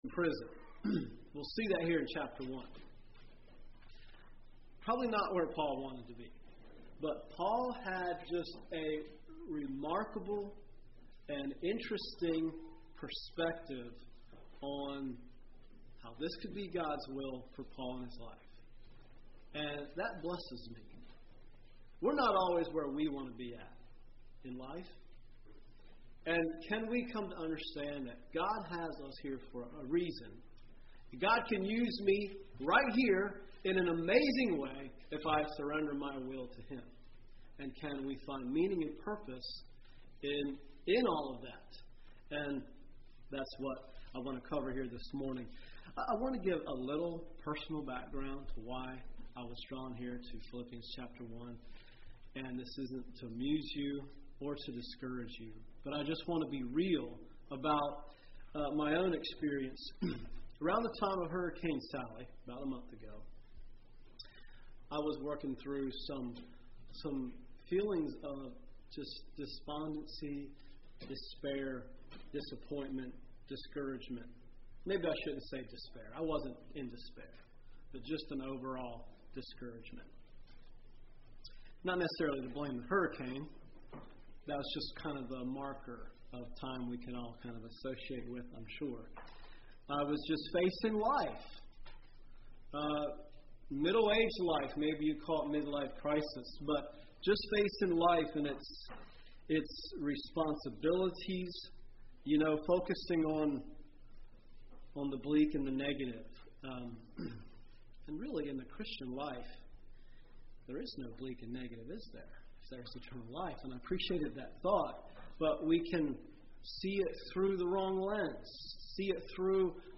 2020 Sermon ID